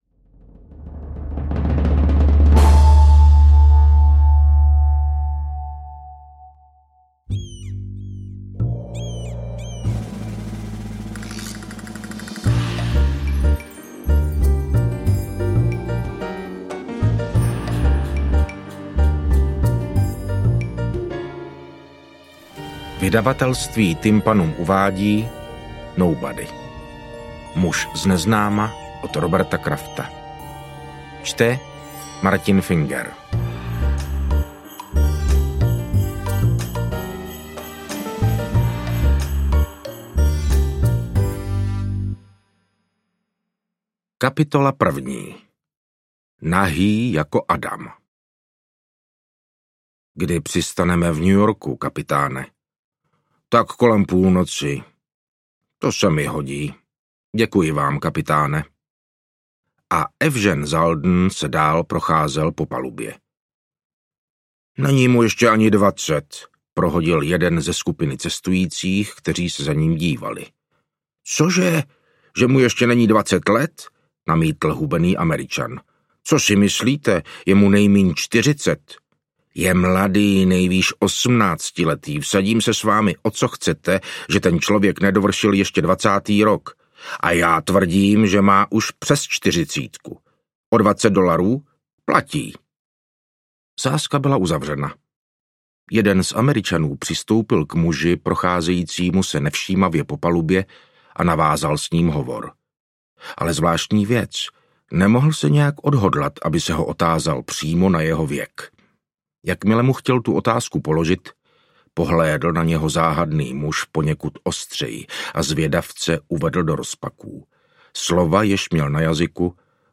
Interpret:  Martin Finger
AudioKniha ke stažení, 16 x mp3, délka 6 hod. 14 min., velikost 342,5 MB, česky